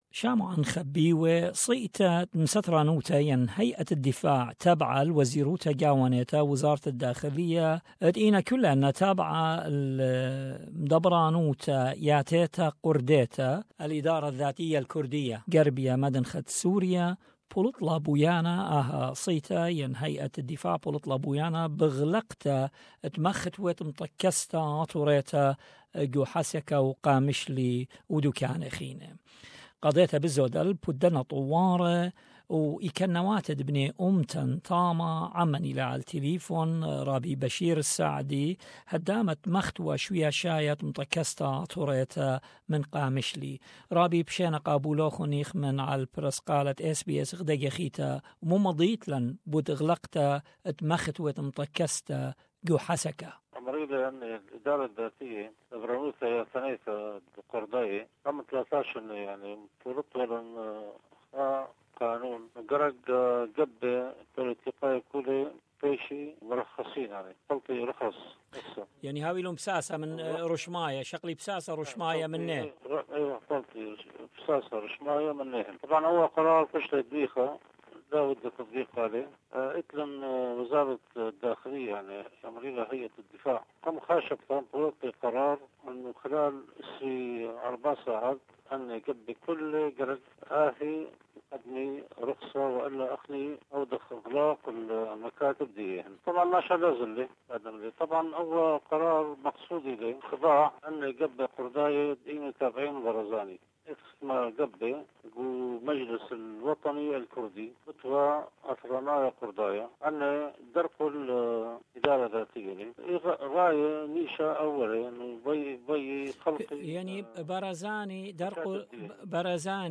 Kurdish police (Asayish) reportedly closed the headquarters of the Assyrian Democratic Organization (ADO) on Thursday night, forcing all the members inside to leave the premises. Interview